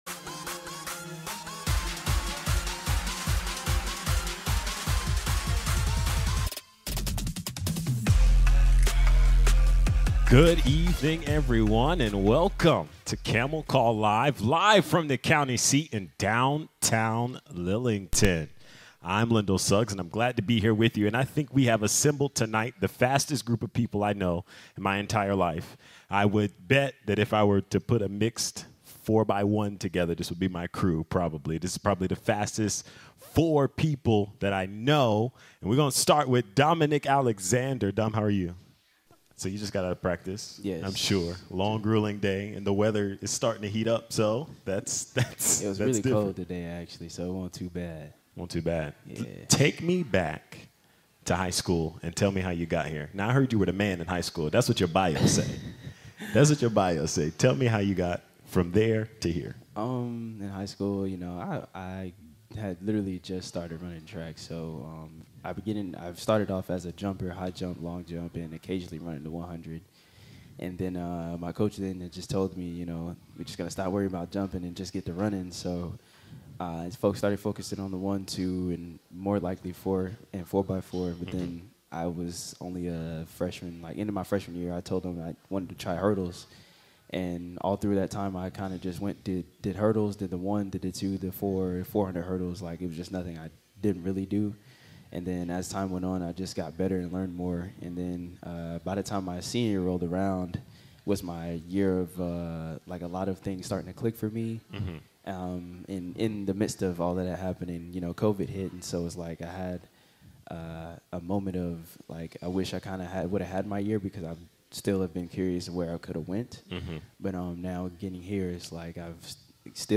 Recorded live from the County Seat in downtown Lillington, this is Camel Call Live.